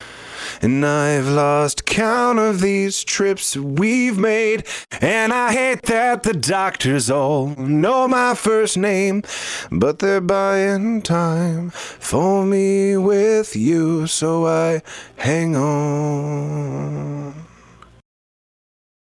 Hab vor kurzem gelesen Leute ballern zusätzlich zum 76+la2a den Devil Loc von Soundtoys parallel drauf, wenns ein bisschen mehr sein darf.